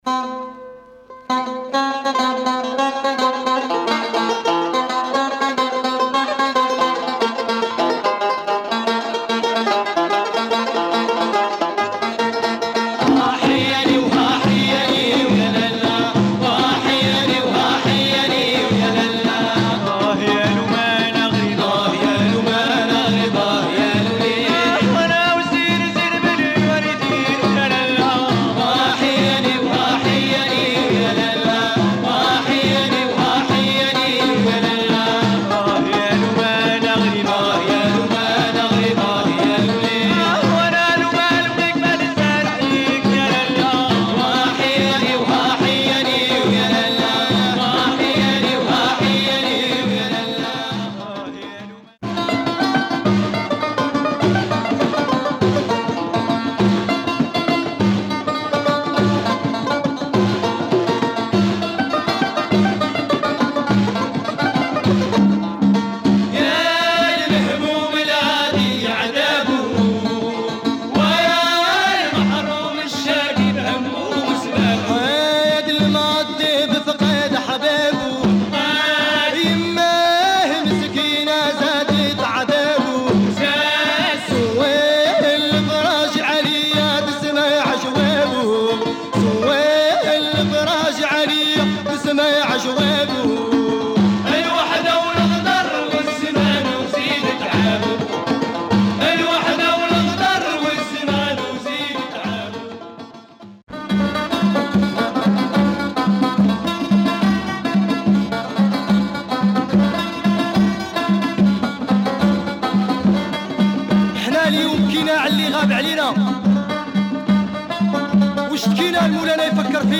Underground 70's Moroccan band, deep arabic folk.